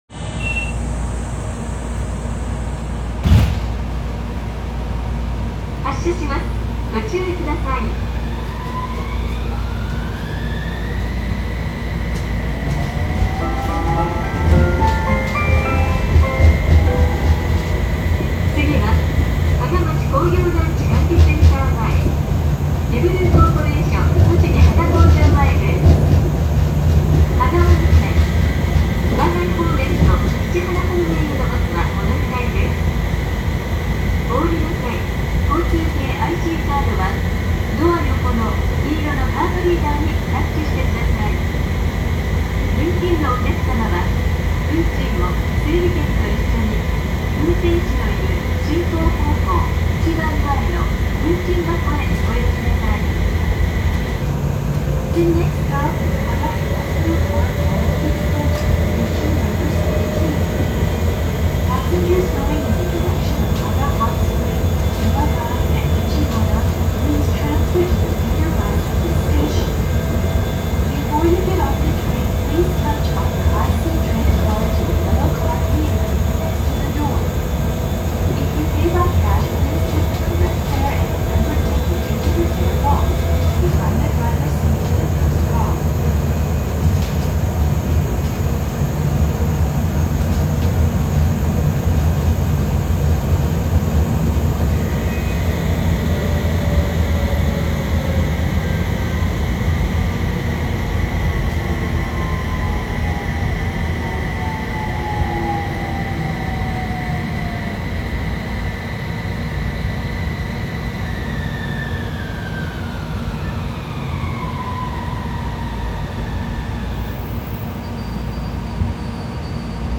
・HU300形走行音
路面電車車両にはありがちな三菱IGBTで特段目立った音ではありません。停車中に少々耳に残るブザーが流れ続けるほか、車内チャイムの種類がやたらと多く、個性はそこに見出せそうです。